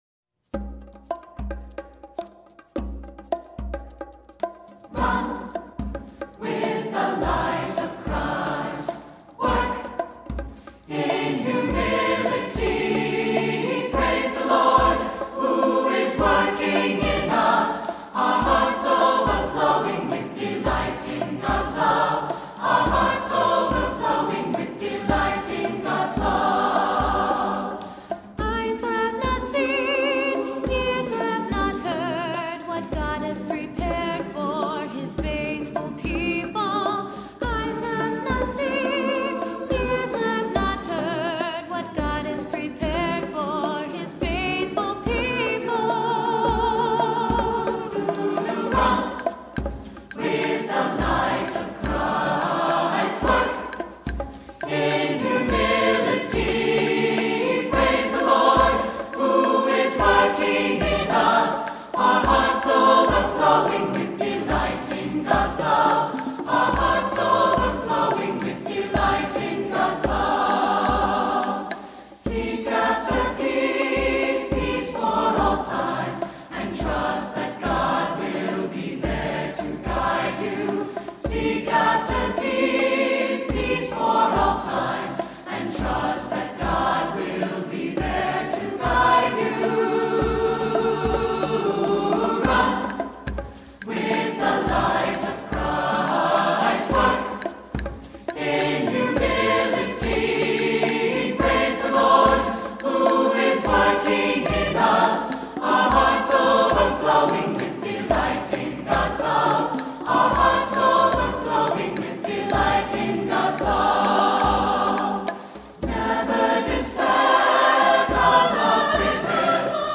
Voicing: Assembly, descant,SATB